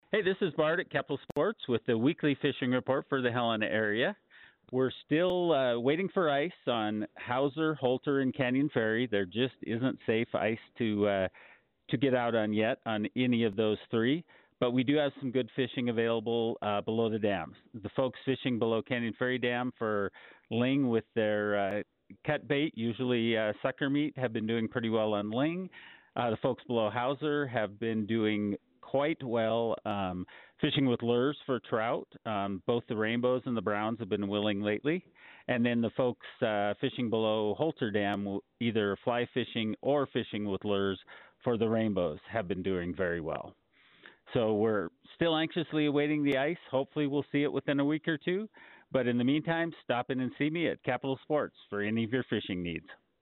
Central Montana Central Montana Fishing Reports Helena Lakes Montana Fishing Reports